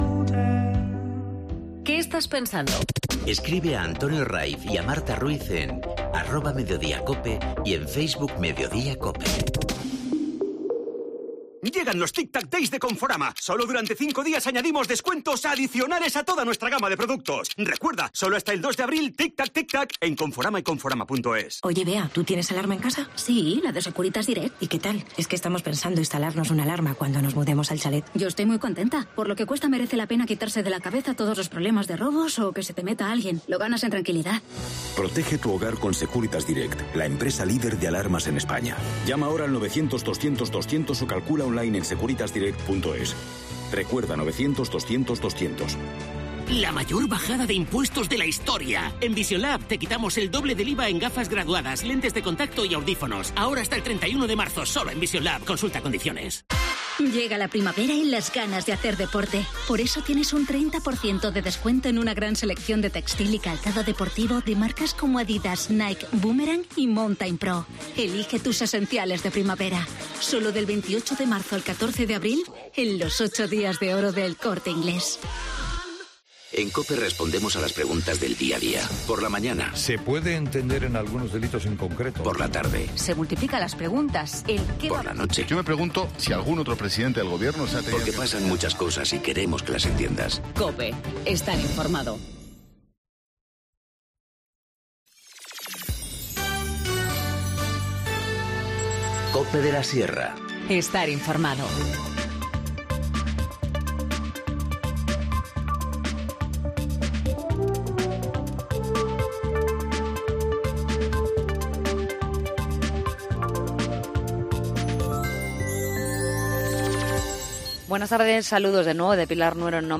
Informativo Mediodía 29 marzo 14:50h